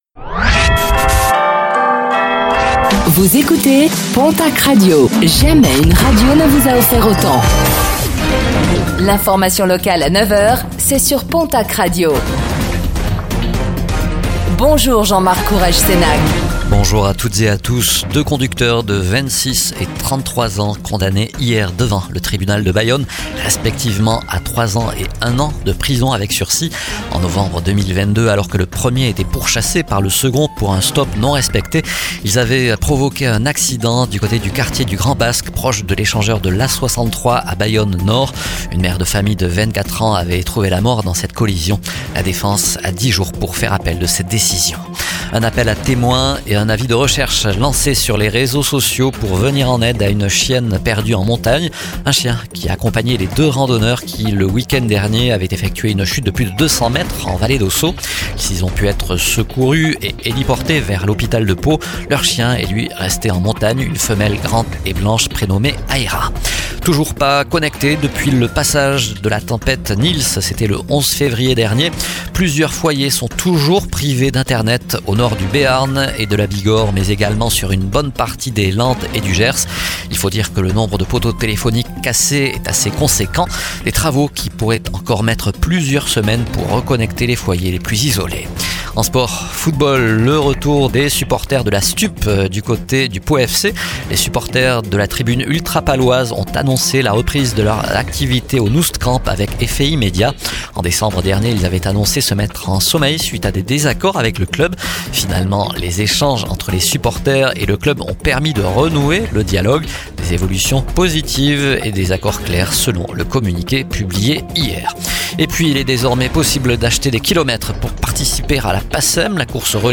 Réécoutez le flash d'information locale de ce mercredi 04 mars 2026